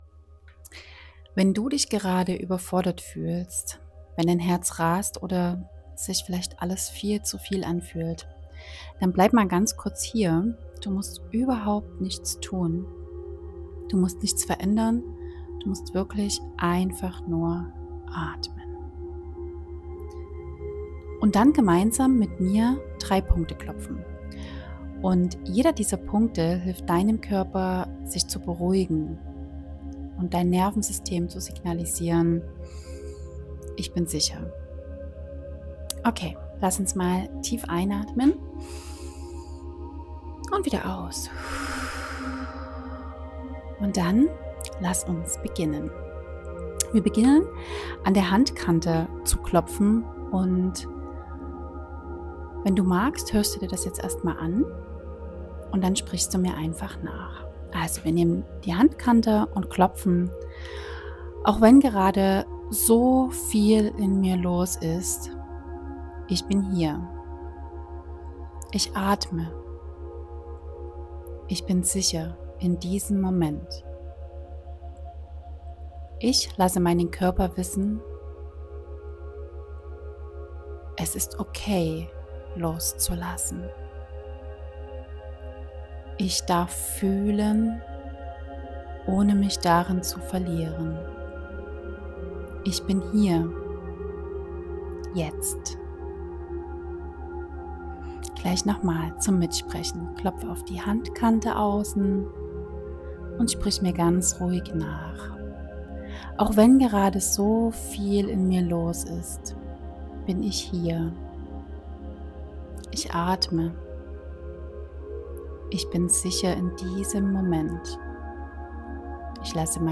Ein kurzer, sanft geführter EFT-Notfalltalk für Momente, in denen